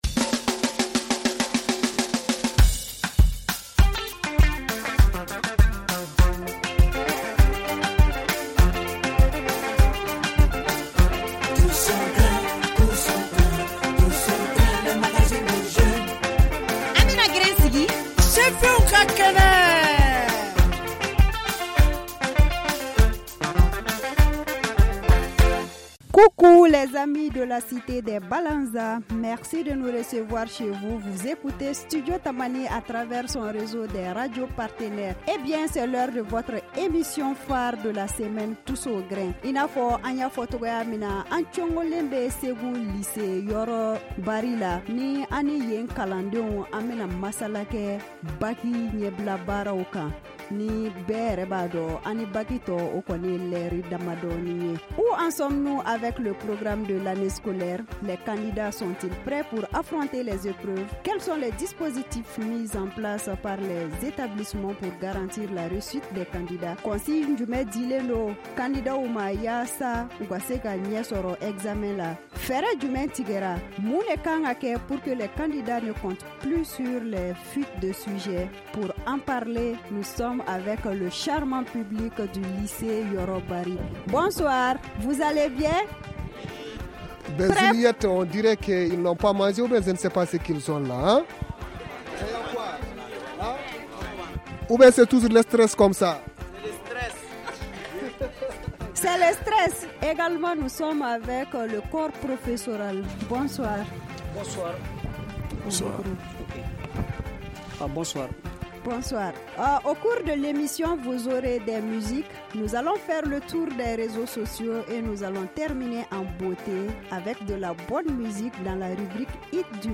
L’équipe du Tous au grin de Studio Tamani était à Ségou cette semaine.